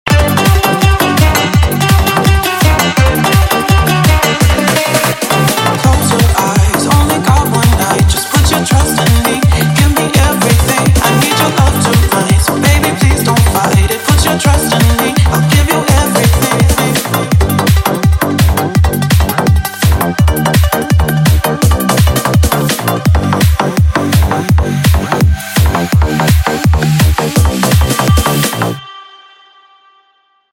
восточные мотивы
быстрые
подвижные
цикличные